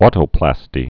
au·to·plas·ty
tō-plăstē)